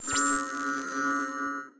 forcefield1.ogg